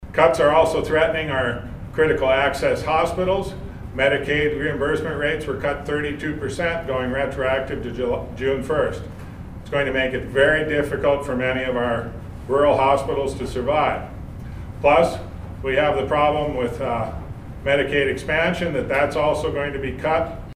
Gubernatorial Candidate Marvin Nelson was part of the press conference at the Gladstone Inn & Suites and opened with the fact that North Dakota was already facing a huge shortfall in the state budget.